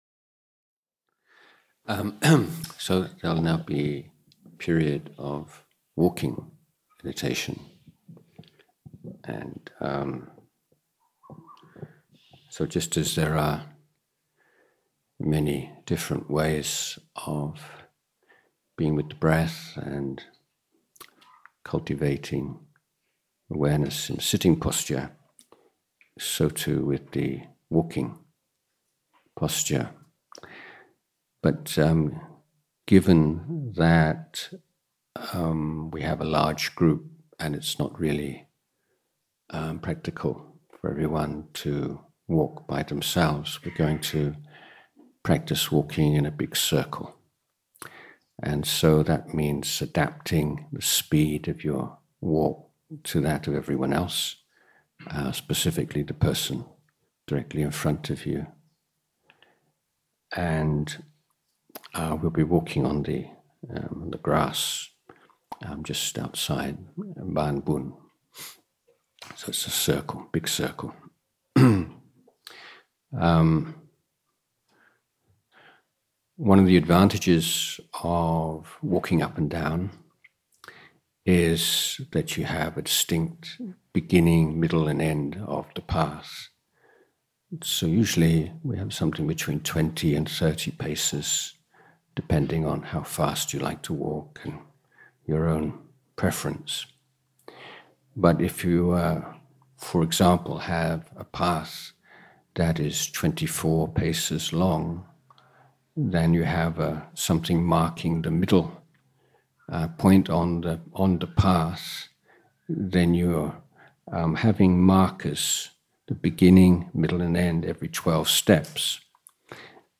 English Retreat at Bahn Boon, Pak Chong, Nakhon Rachasima, 20-24 March 2024